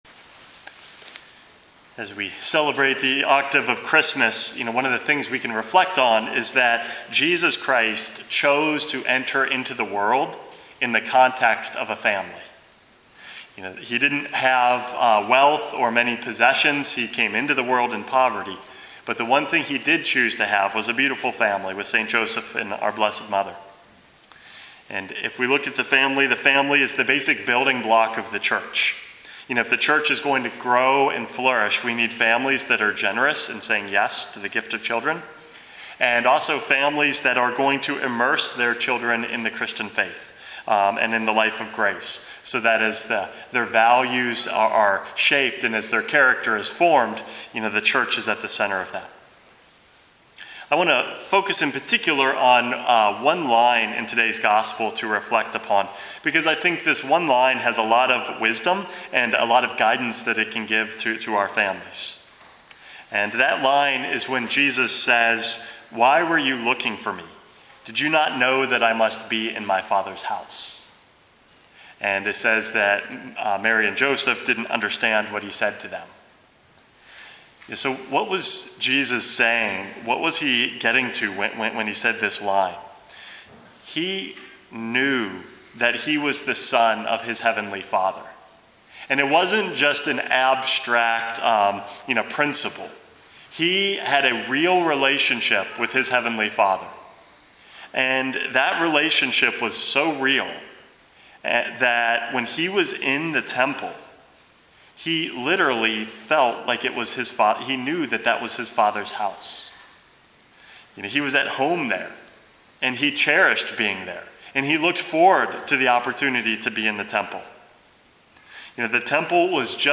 Homily #432 - Recognizing Our True Home